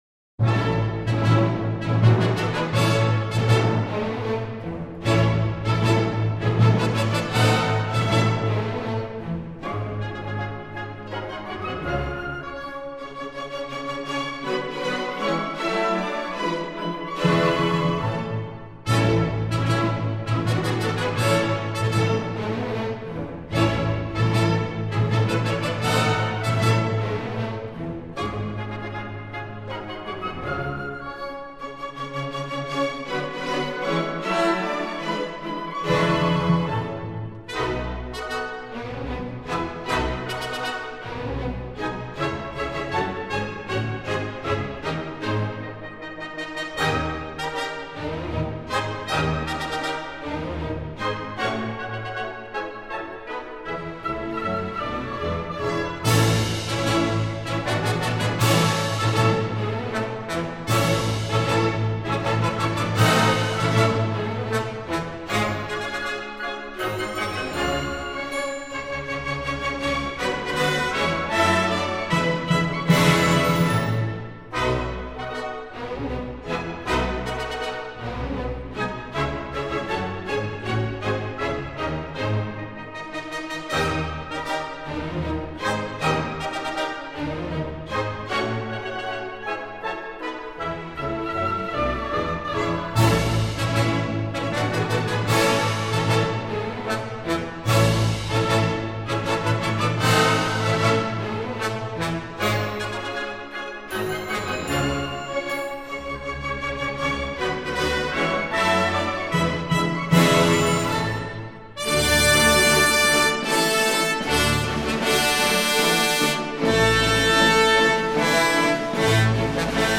Polonezas A-dur (A. Glazunovo orkestruotė)
Jam būdingas trijų dalių metras, pasikartojanti ritmo formulė.